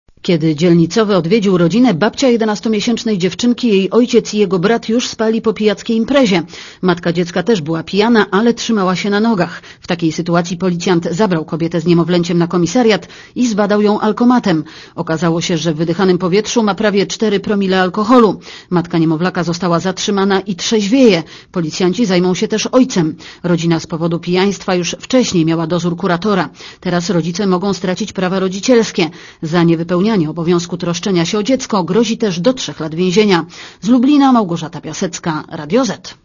Posłuchaj relacji reporterki Radia Zet (144 KB)